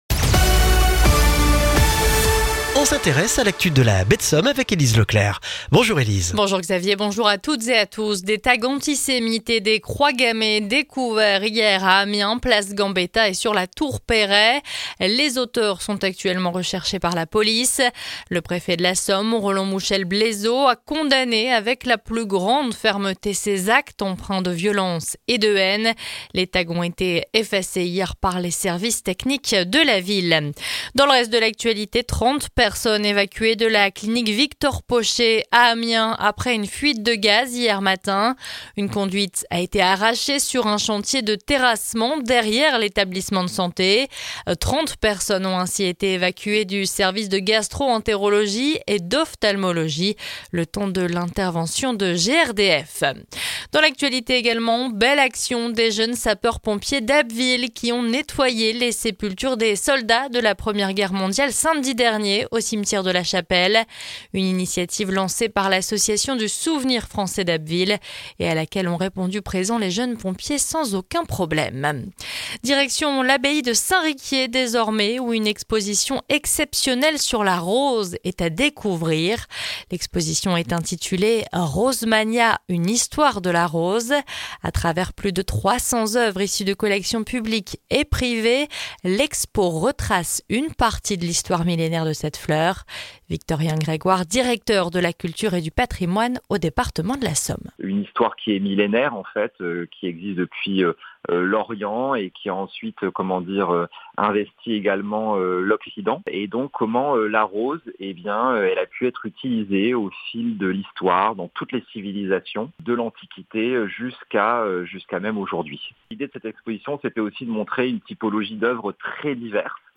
Le journal du mercredi 13 novembre en Baie de Somme et dans la région d'Abbeville